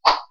swordhit.wav